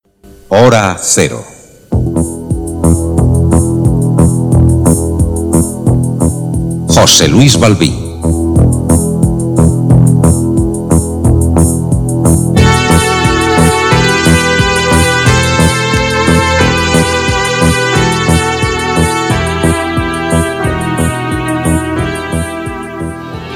Careta del programa
Informatiu